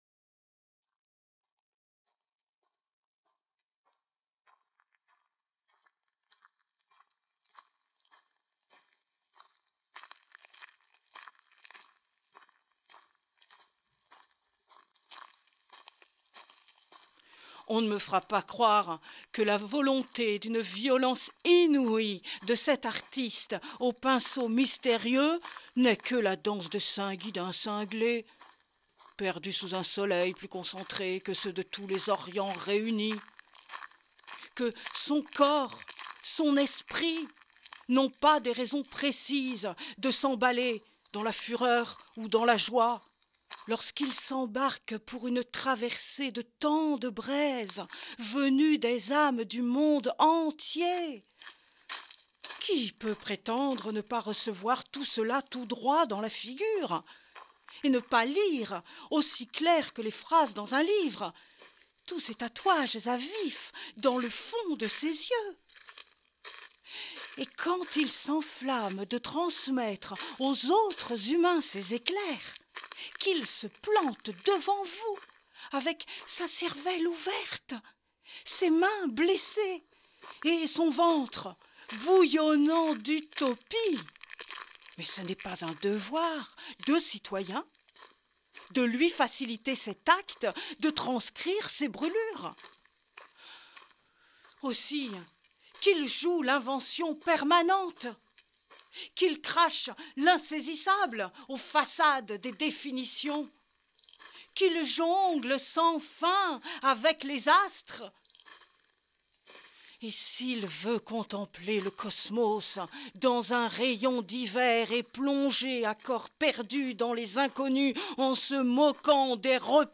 Dramatique radiophonique de la pièce Et tes soleils (50′) enregistrée en 2012 aux caves de l’atelier C à Arles